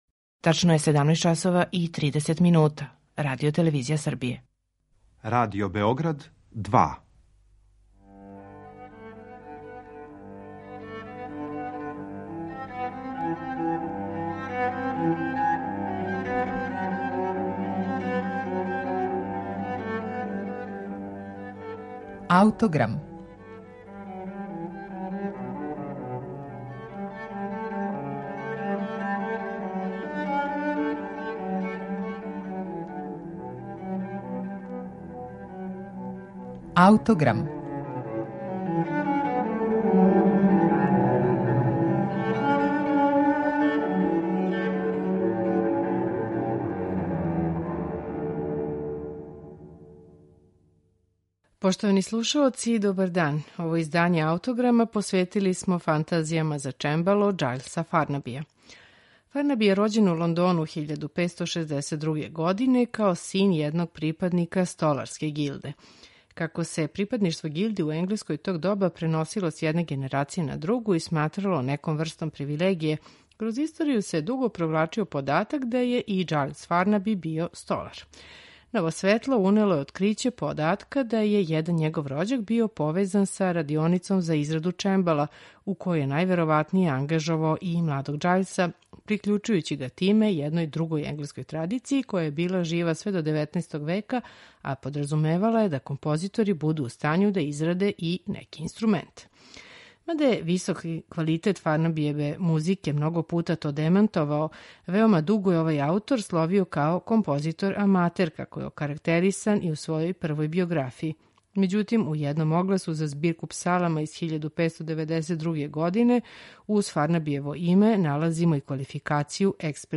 за чембало